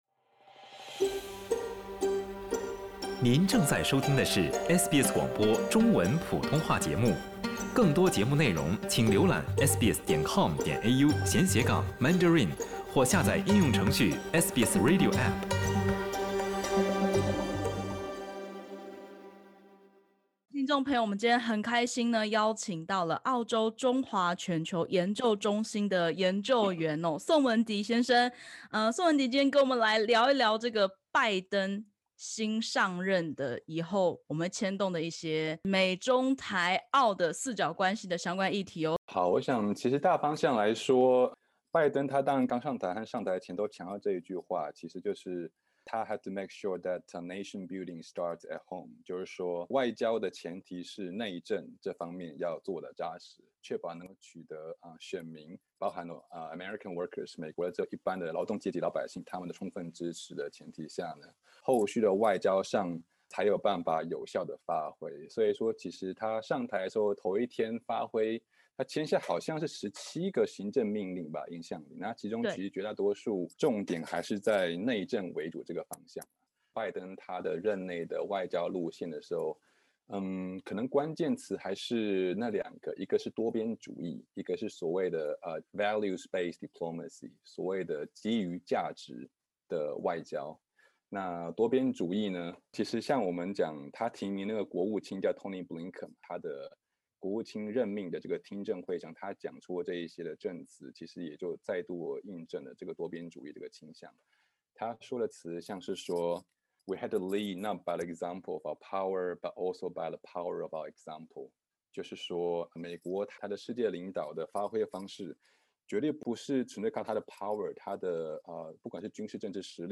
美国总统拜登即将就任半年，学者分析，拜登的外交政策有两大核心：多边主义和树立基於价值的外交。点击首图收听采访音频。